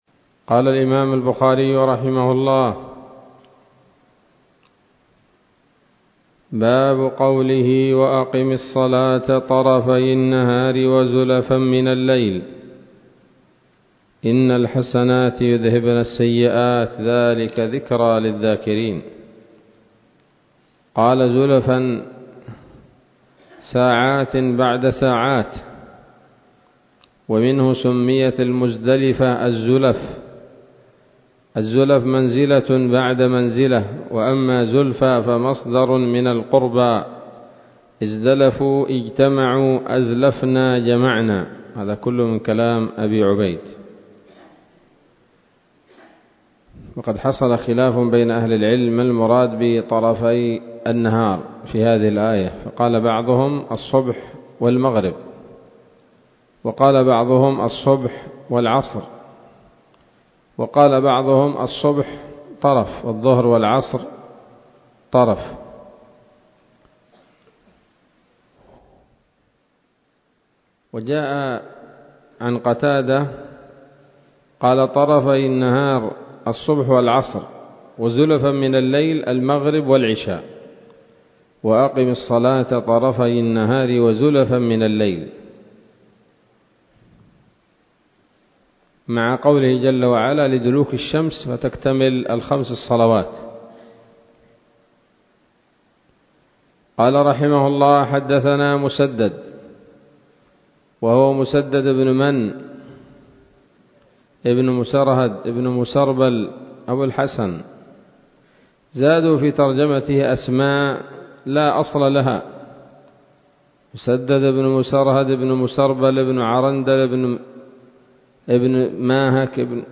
الدرس الثامن والثلاثون بعد المائة من كتاب التفسير من صحيح الإمام البخاري